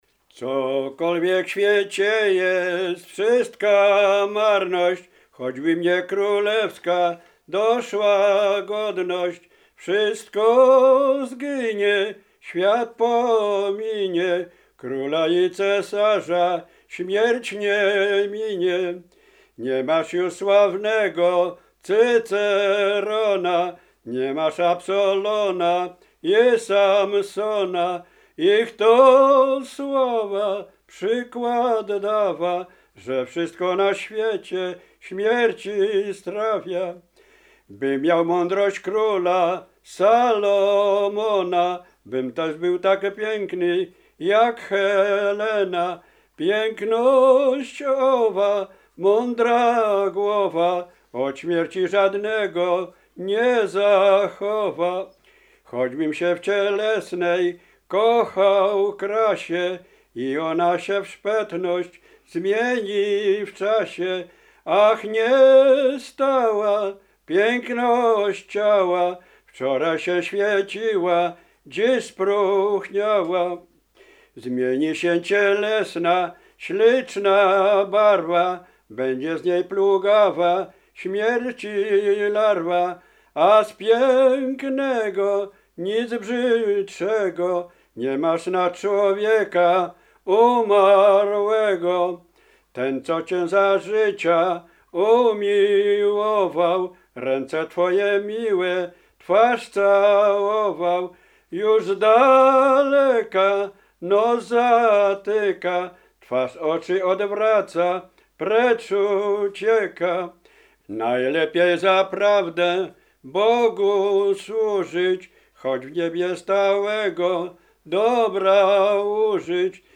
Powiśle Maciejowickie
Pogrzebowa
pogrzebowe katolickie nabożne